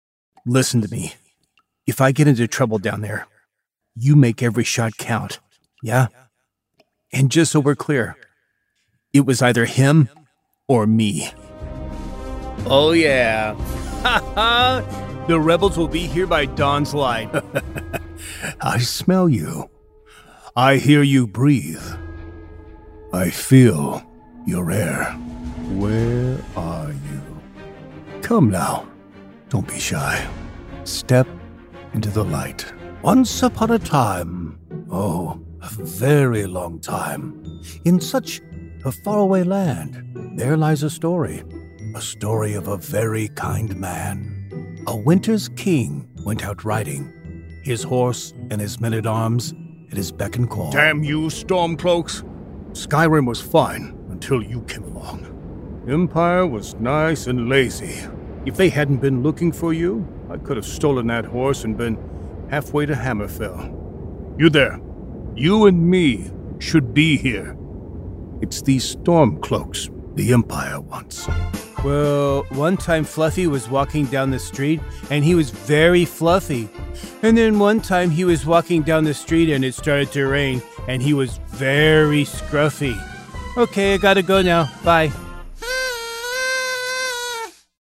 Male
Trans-Atlantic
I have a dynamic voice range, from conversational and relatable, to energetic and powerful announcer.
Microphone: Neumann U87, Sennheiser MKH416